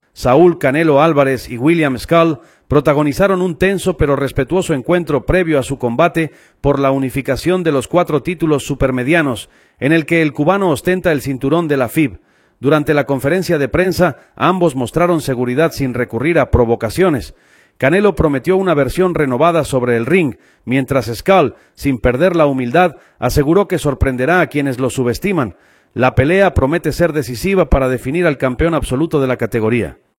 Durante la conferencia de prensa, ambos mostraron seguridad sin recurrir a provocaciones. Canelo prometió una versión renovada sobre el ring, mientras Scull, sin perder la humildad, aseguró que sorprenderá a quienes lo subestiman.